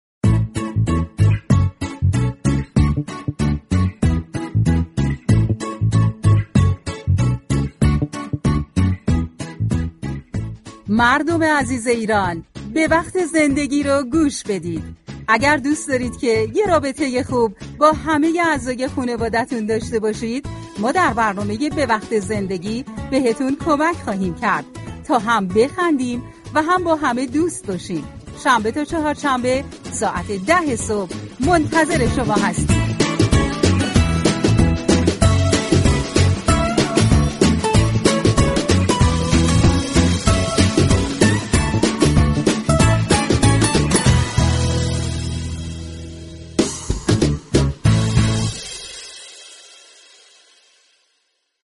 روز چهارشنبه 28 مهر در برنامه به وقت زندگی از طریق تعامل با مخاطبان و پخش آیتم ها وگزارش های مردمی برای مخاطبان راهكارهایی برای برخورد با افراد بی مسئولیت در خانواده و جامعه ارایه می شود .